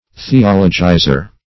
Theologizer \The*ol"o*gi`zer\, n.
theologizer.mp3